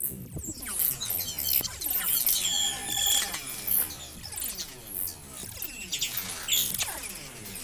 Category 🌿 Nature
ambient animal bird birdsong delay dub echo effect sound effect free sound royalty free Nature